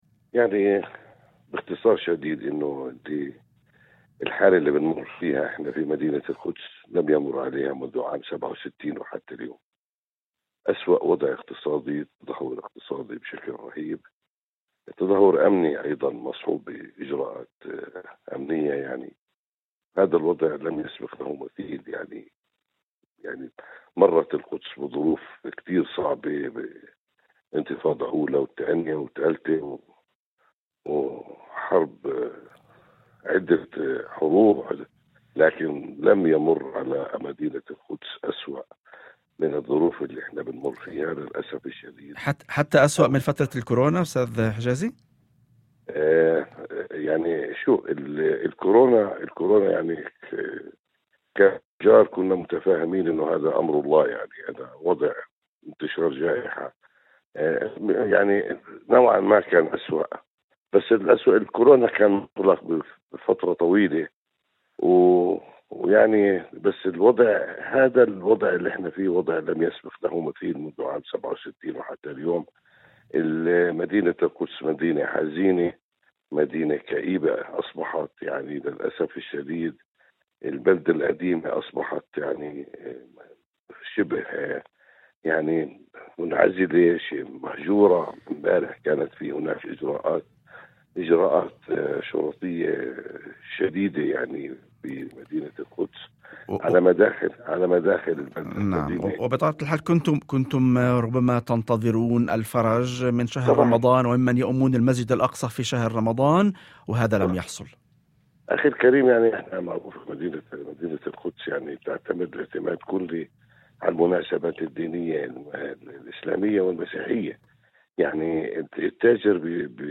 وأضاف في مداخلة هاتفية لبرنامج "يوم جديد" ، على إذاعة الشمس، أن التجار كانوا يأملون أن يشكل شهر رمضان فرصة لتحريك الأسواق، كما جرت العادة في كل عام، إذ تعتمد الحركة التجارية في القدس بشكل كبير على المواسم الدينية الإسلامية والمسيحية.